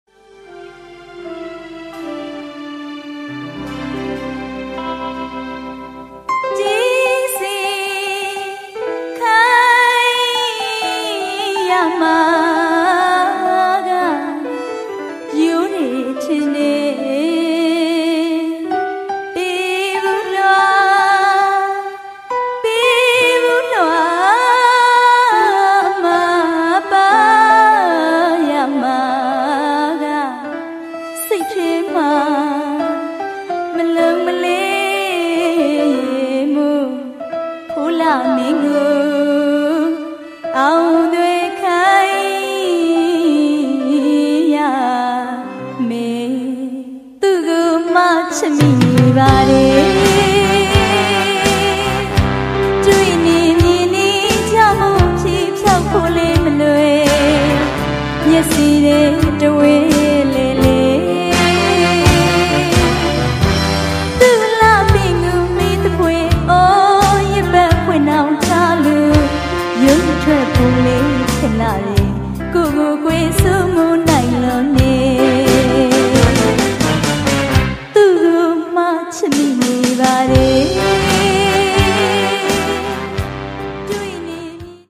80〜90年代の録音が中心と思われ
で、基本、今につながるミャンマー・タンズィン・スタイルですね。